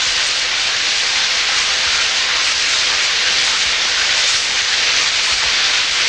Showering Sound Effect
showering.mp3